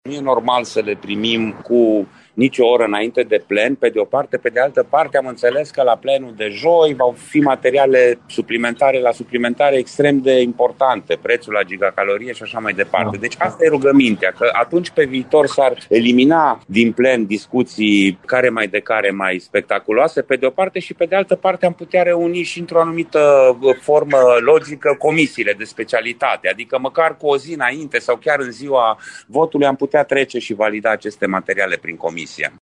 Consilierul PSD Radu Țoancă a solicitat ca aleșilor locali să li se dea timp să studieze proiectele pe care le votează: